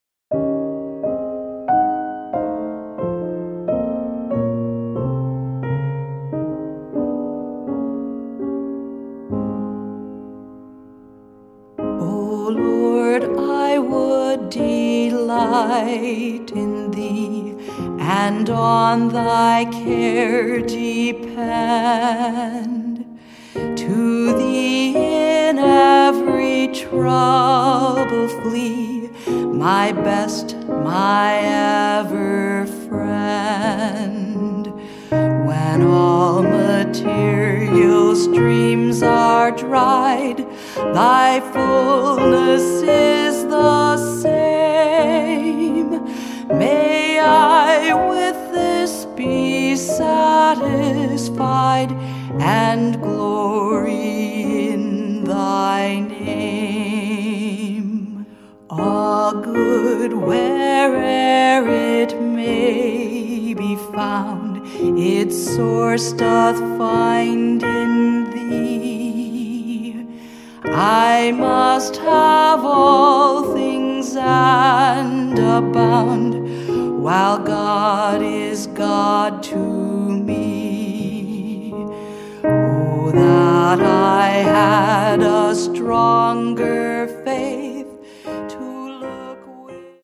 this hymn.
02Track-Hymn224.mp3